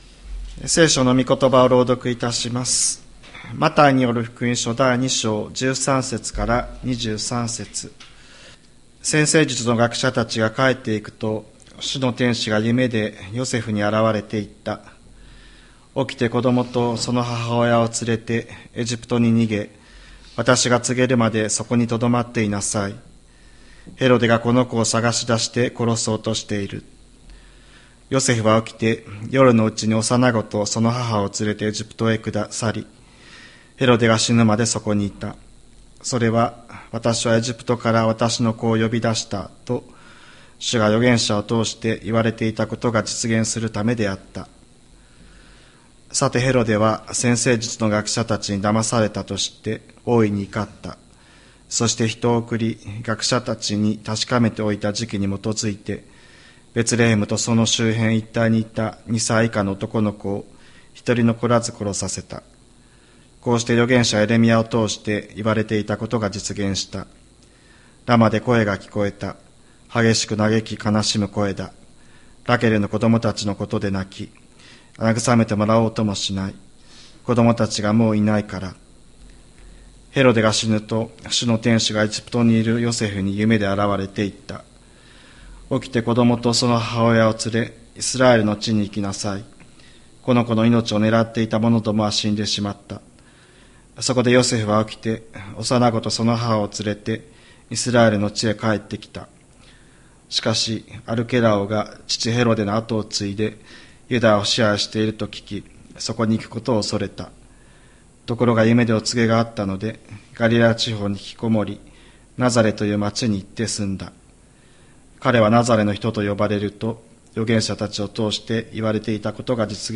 千里山教会 2024年12月29日の礼拝メッセージ。